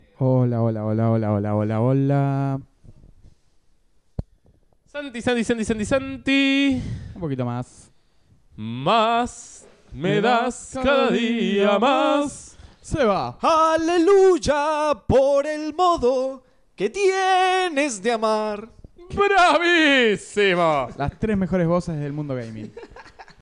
Les dejamos un pequeño backstage de la prueba de micrófonos antes de grabar